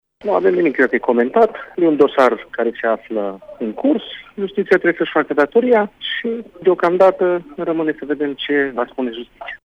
Nici viceprimarul municipiului, Claudiu Maior, nu vrea să comenteze până când justiția nu va da un verdict clar: